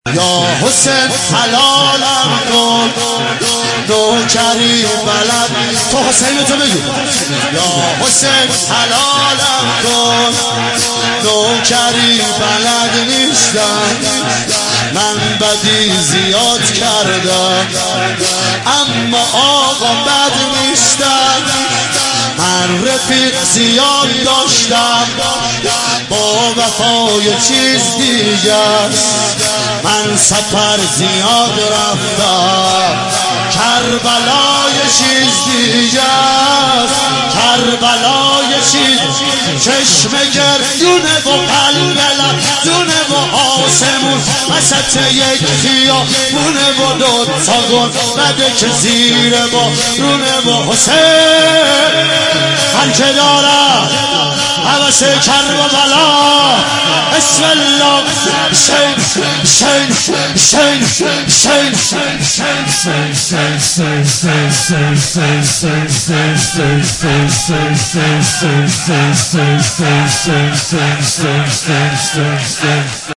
شور